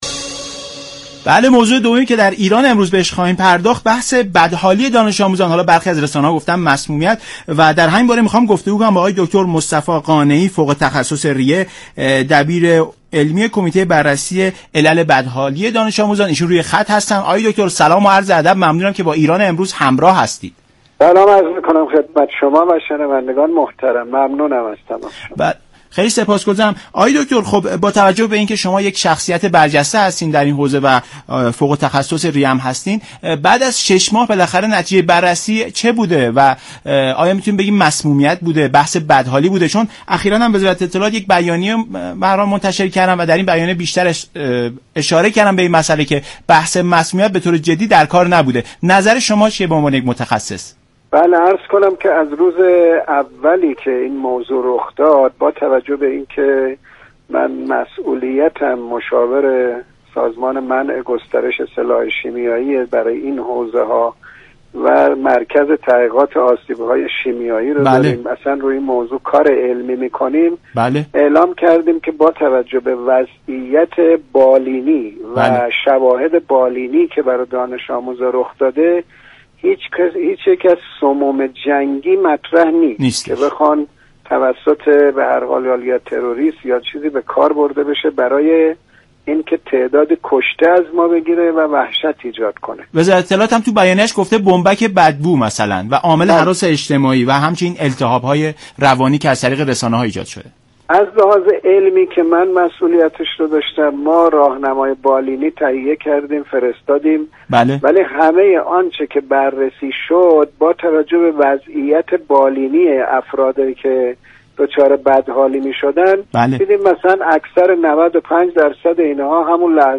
دكتر مصطفی قانعی فوق تخصص ریه و دبیر علمی كمیته بررسی علل بدحالی دانش آموزان گفت: شواهد بالینی نشان می دهد مسمویت هایی كه طی چند ماه گذشته در مدارس به وجود آمد، ناشی از سموم شیمیایی نبوده است.
برنامه ایران امروز شنبه تا سه شنبه هر هفته ساعت 12:40 از رادیو ایران پخش می شود.